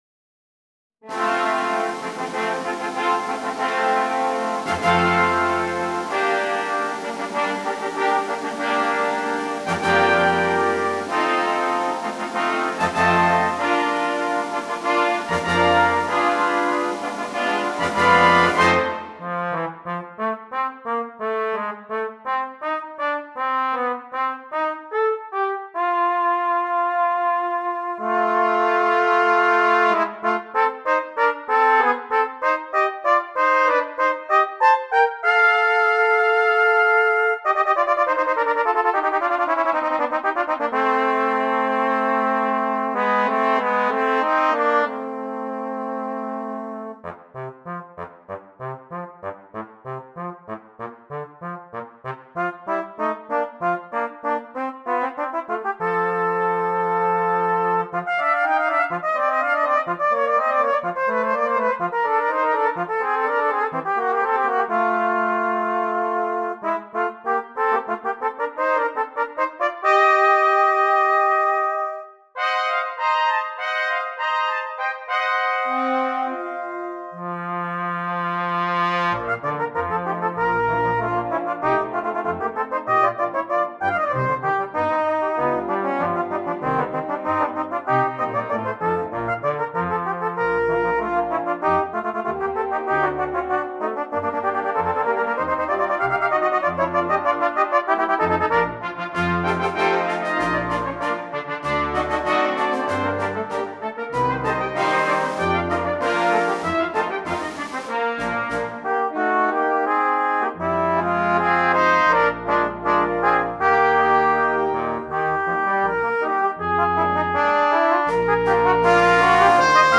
Brass Choir (5.4.4.1.1.perc)
a cornet and trombone duet with band accompaniment
for a brass choir (5.4.4.1.1.perc) accompanying the soloists